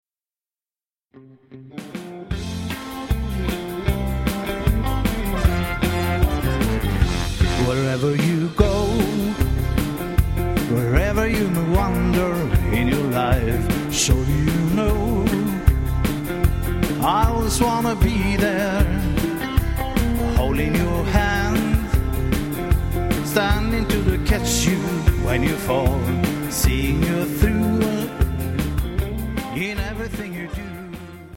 Vi har resurser att låta som ett betydligt större band.
• Coverband
• Country